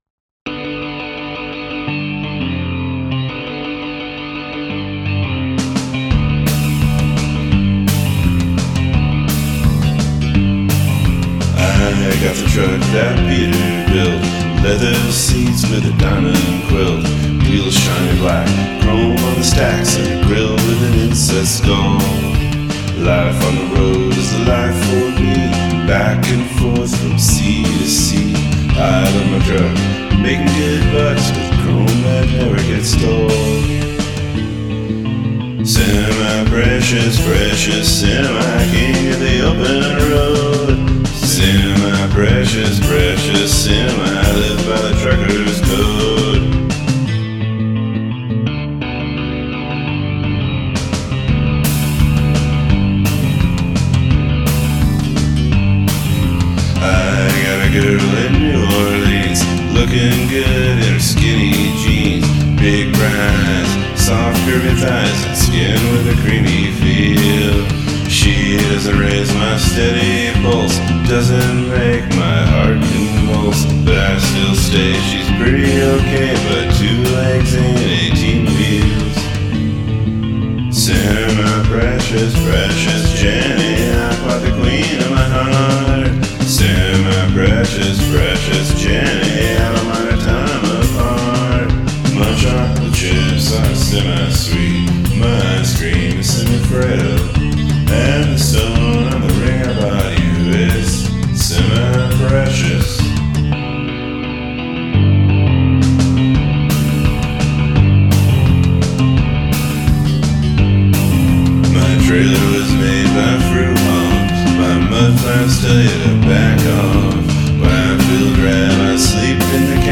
Gradual emphasis of repetitions